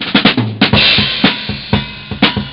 my Yamaha Drumset
drums.wav